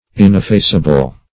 Search Result for " ineffaceable" : The Collaborative International Dictionary of English v.0.48: Ineffaceable \In`ef*face"a*ble\, a. [Pref. in- not + effaceable: cf. F. ineffa[,c]able.]